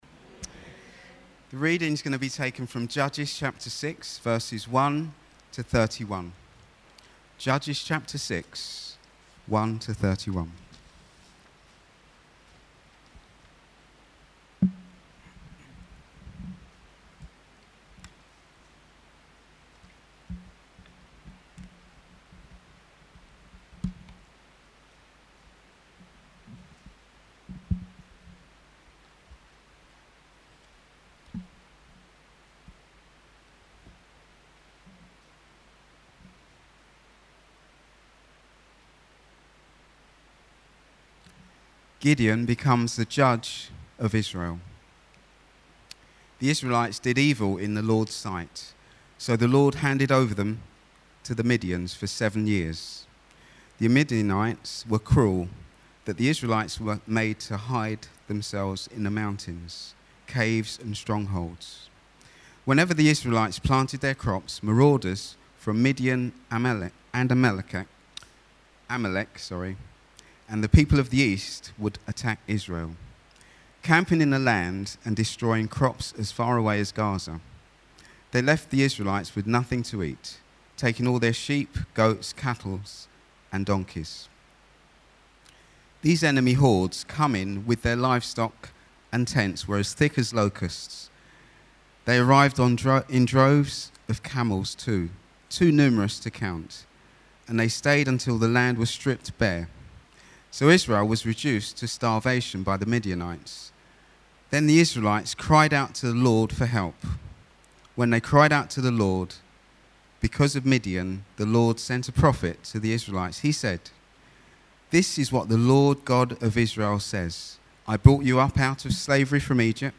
A Sermon during the Sunday service at East Hill Baptist Church